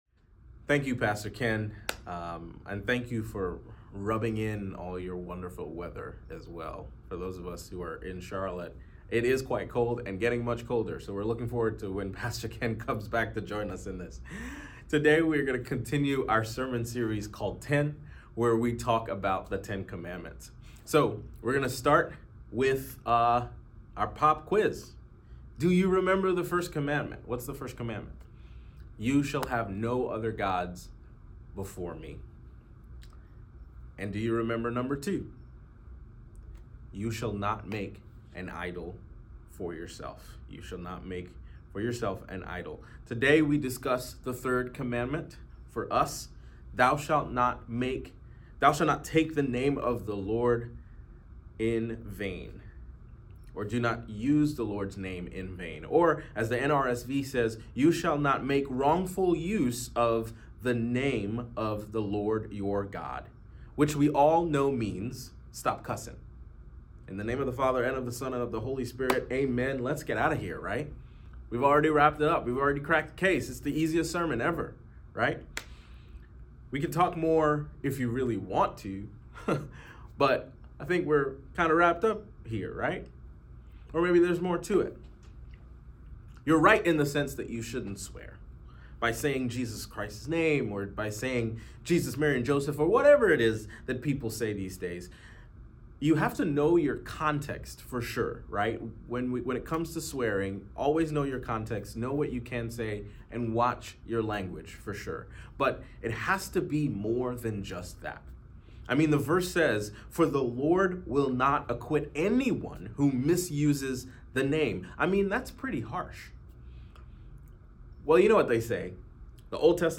Sermon Reflections: